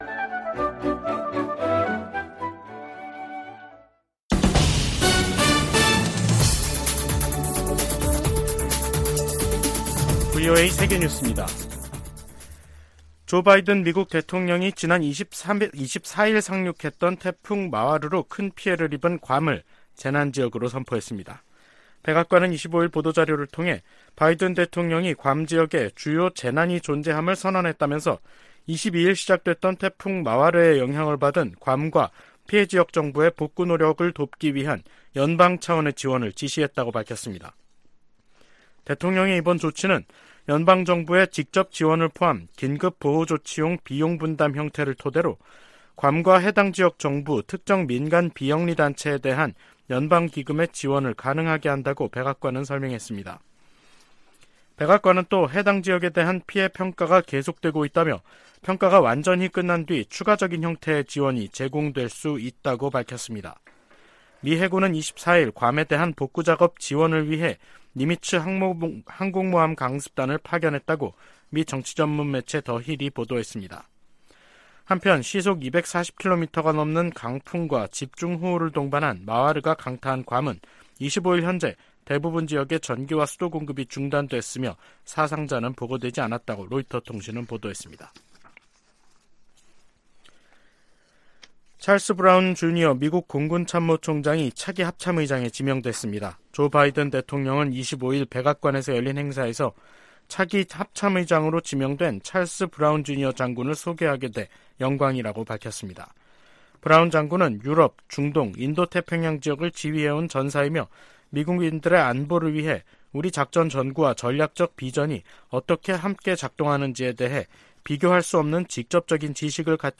VOA 한국어 간판 뉴스 프로그램 '뉴스 투데이', 2023년 5월 26일 3부 방송입니다. 한국이 자력으로 실용급 위성을 궤도에 안착시키는데 성공함으로써 북한은 우주 기술에서 뒤떨어진다는 평가가 나오고 있습니다. 워싱턴 선언은 한국에 대한 확정억제 공약을 가장 강력한 용어로 명시한 것이라고 미 고위 당국자가 평가했습니다. 미국은 중국의 타이완 공격을 억제하기 위해 동맹국들과 공동 계획을 수립해야 한다고 미 하원 중국특별위원회가 제언했습니다.